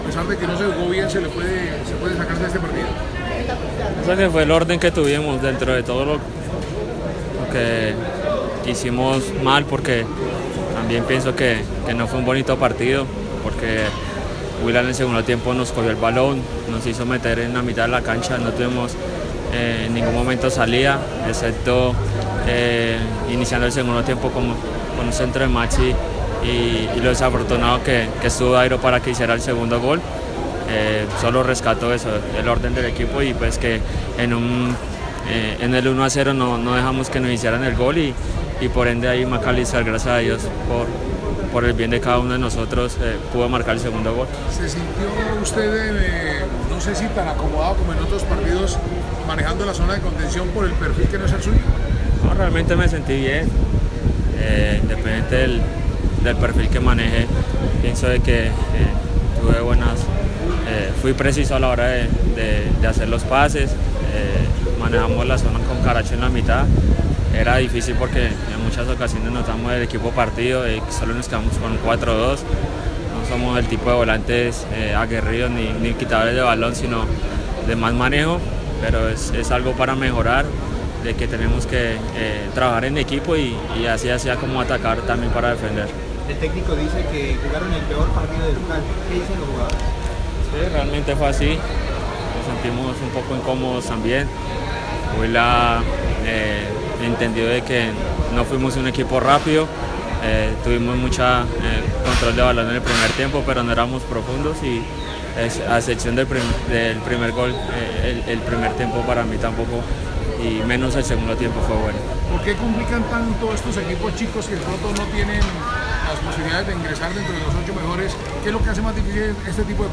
Otro de los jugadores que habló con los medios luego de la victoria sobre el Huila fue Henry Rojas, quien también mostró bastante autocrítica y reconoció los errores del equipo durante el segundo tiempo. El volante resaltó las distintas variables que hicieron que Millonarios no jugara un buen partido.